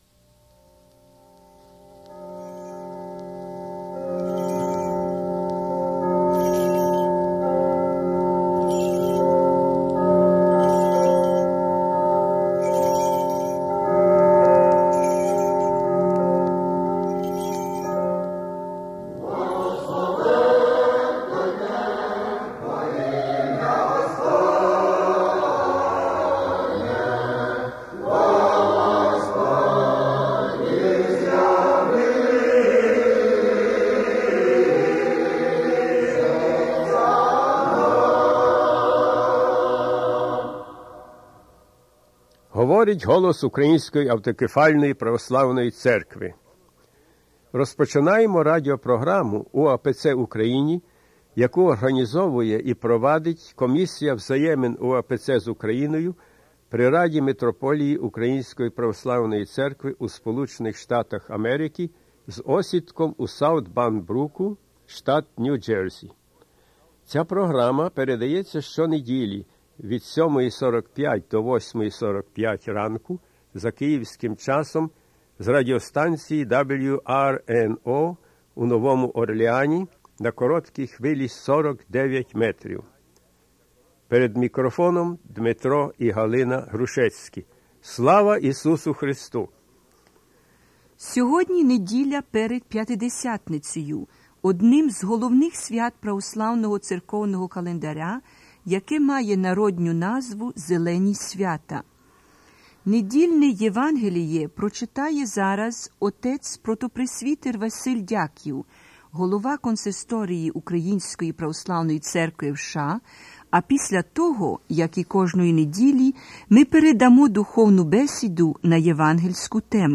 Gospel reading and "spiritual discussion"
Choral rendition of "Alyluia"
Program announcement